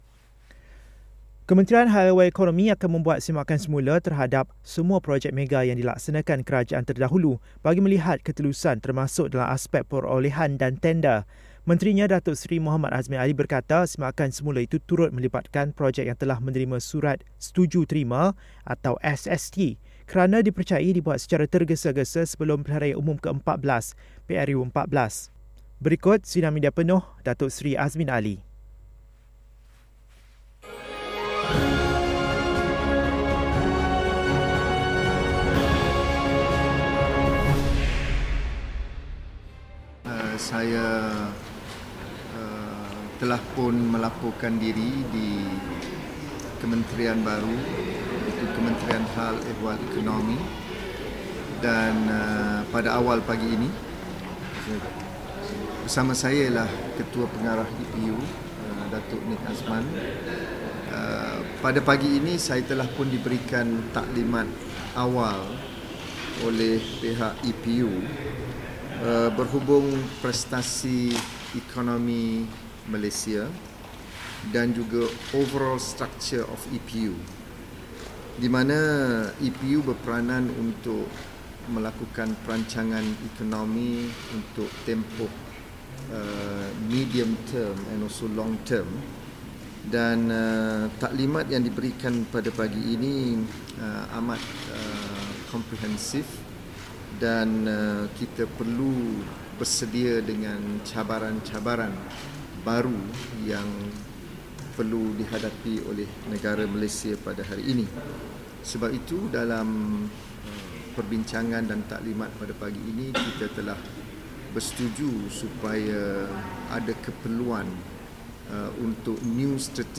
Berikut sidang media penuh Datuk Seri Azmin Ali.